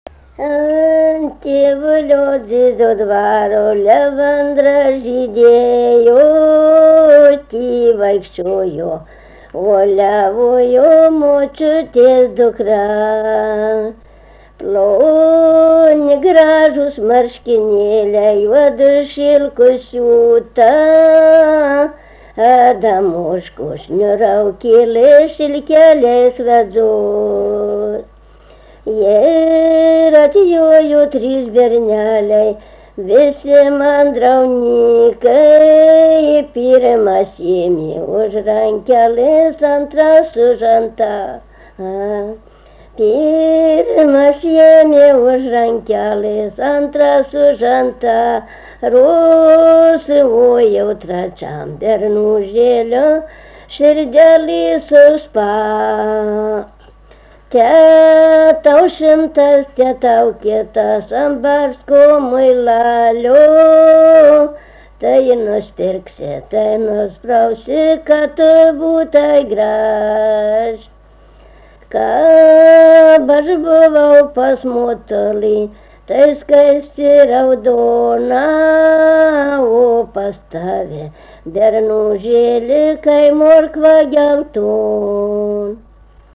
vokalinis
LMTA Mokslo centro muzikinio folkloro archyvas